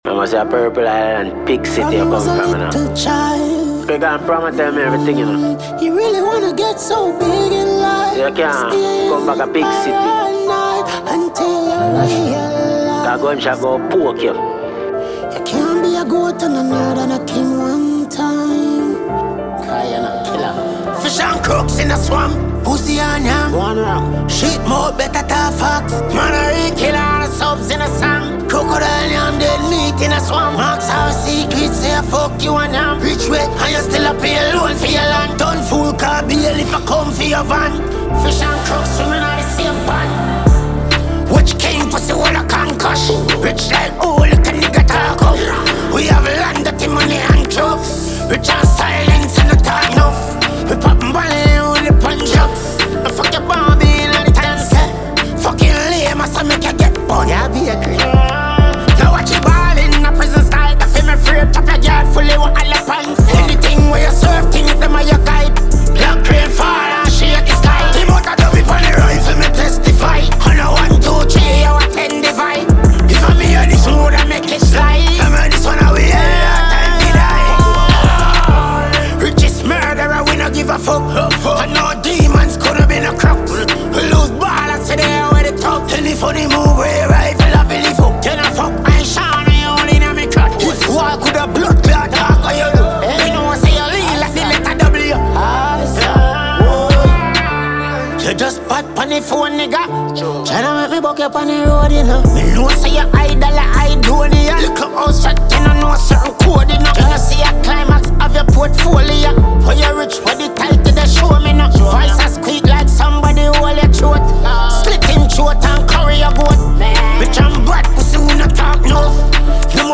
Dancehall
bold, gritty, and unapologetically hardcore